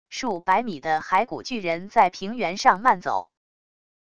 数百米的骸骨巨人在平原上慢走wav音频